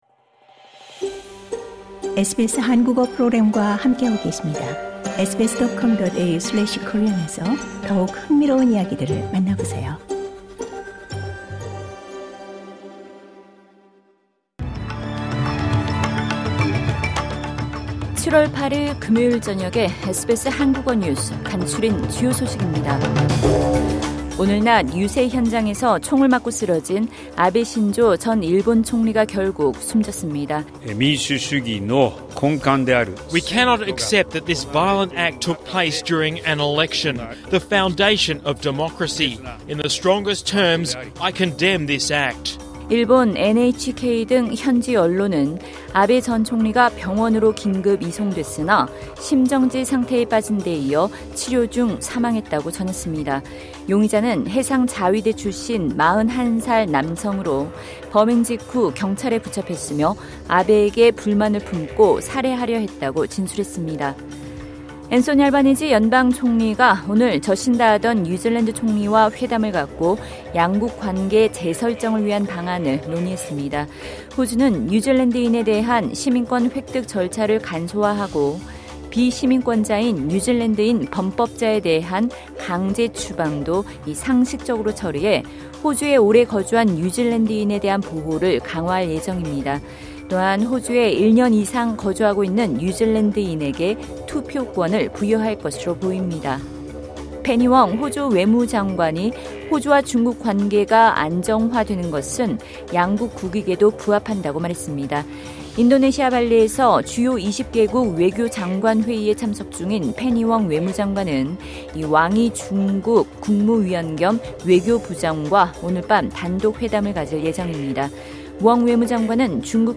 2022년 7월 8일 금요일 저녁 SBS 한국어 간추린 주요 뉴스입니다.